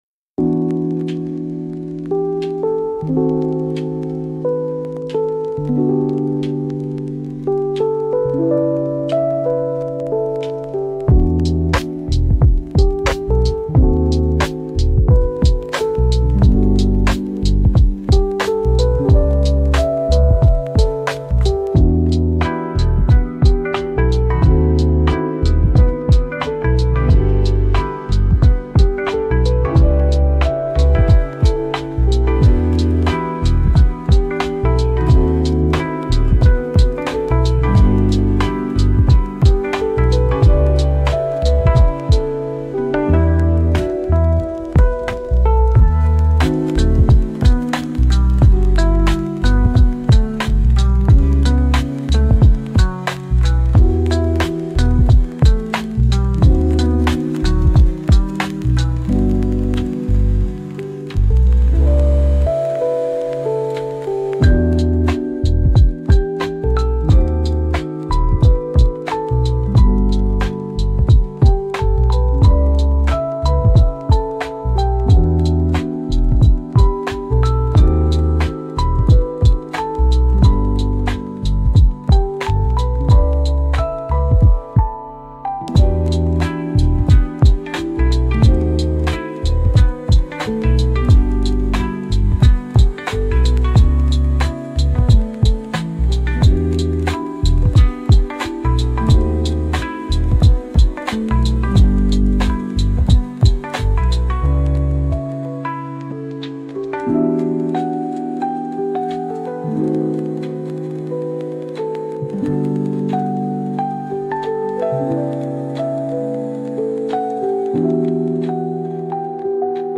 Lofi-Study-Music-hip-hop.mp3